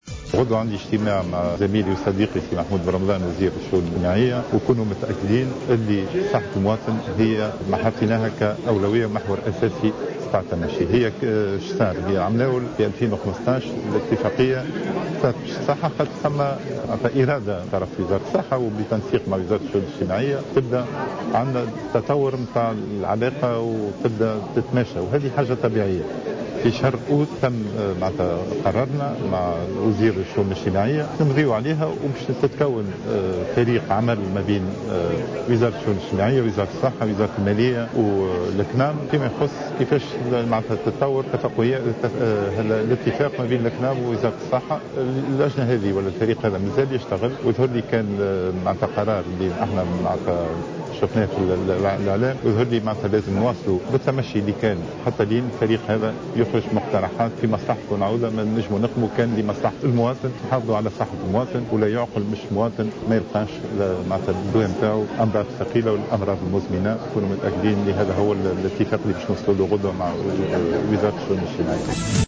وزير الصحة